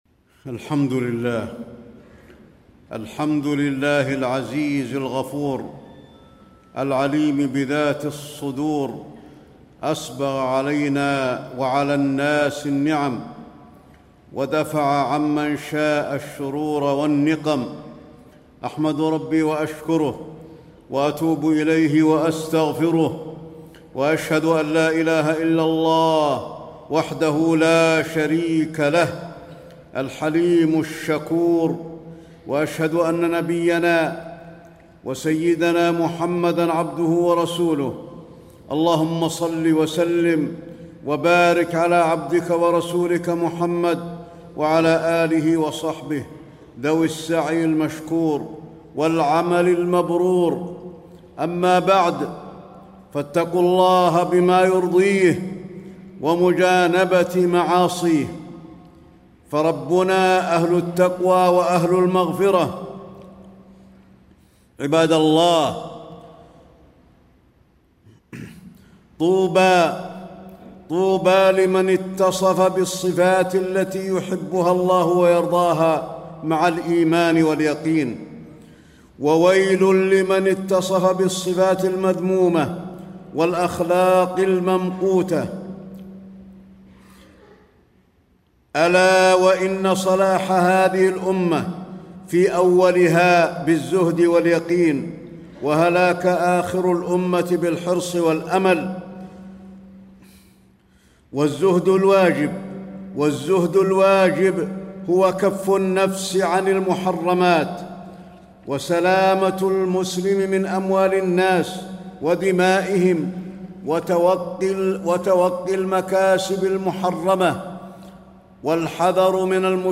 تاريخ النشر ٣ صفر ١٤٣٥ هـ المكان: المسجد النبوي الشيخ: فضيلة الشيخ د. علي بن عبدالرحمن الحذيفي فضيلة الشيخ د. علي بن عبدالرحمن الحذيفي الحذر من الحرص على الدنيا